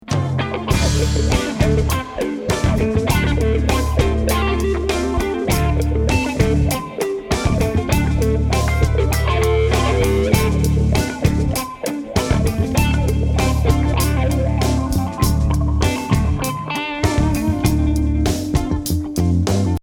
je suis en train de restaurer un vieux enregistrement live que j'avais fait lors d'un concert de mon groupe...
sur le mix-live on se rend vite compte que la caisse claire et la grosse caisse manquent de présence
==>> extrait-2 et c'est encore bien pire quand qu'il y a le chant